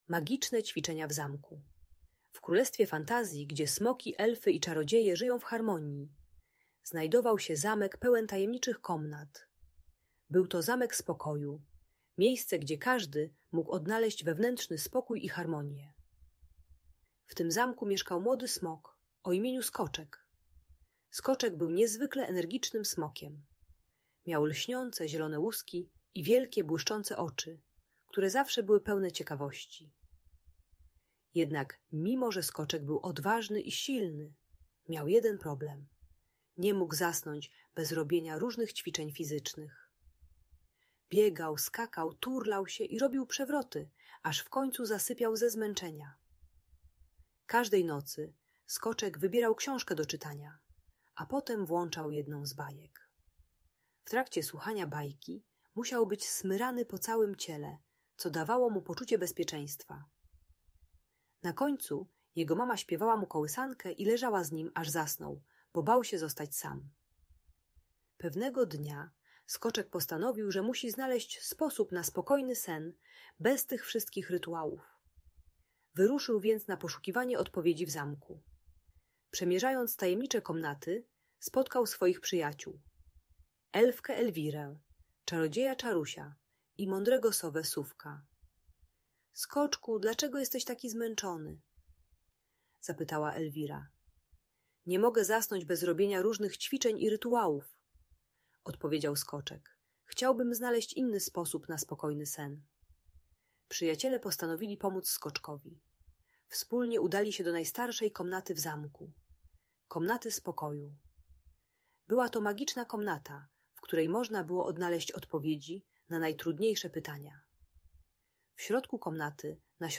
Magiczne Ćwiczenia w Zamku - Audiobajka